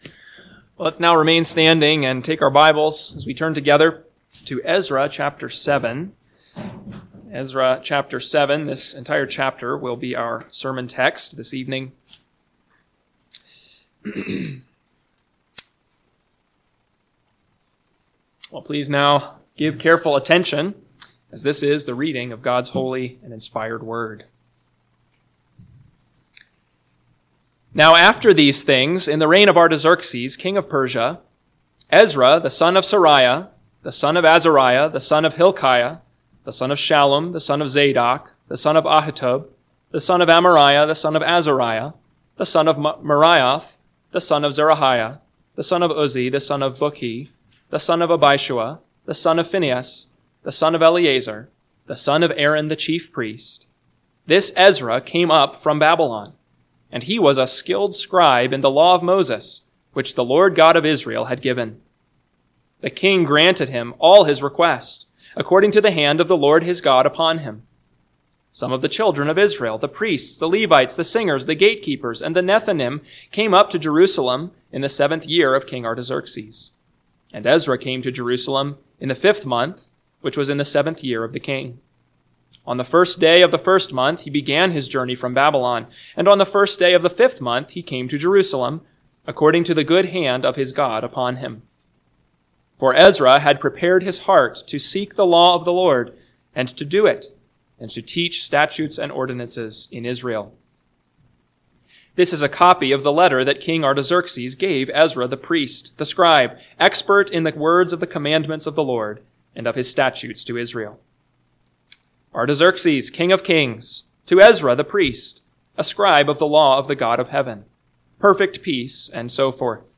PM Sermon – 4/27/2025 – Ezra 7 – Northwoods Sermons